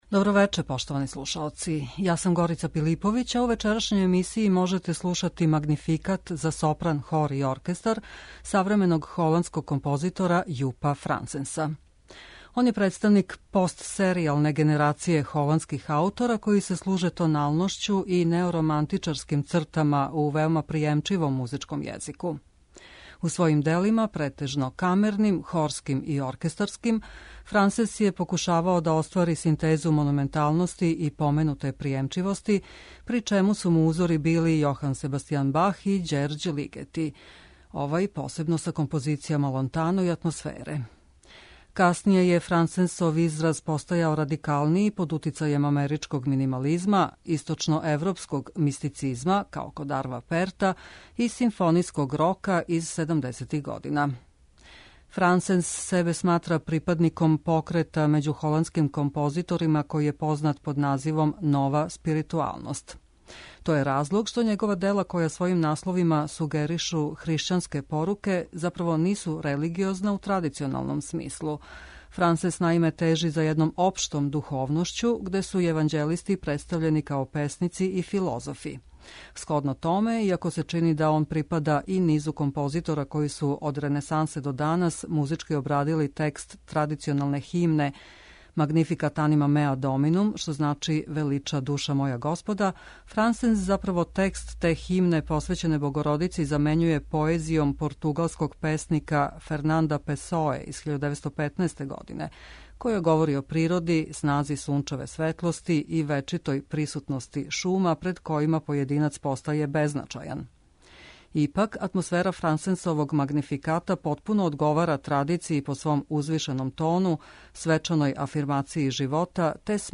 за сопран, хор и оркестар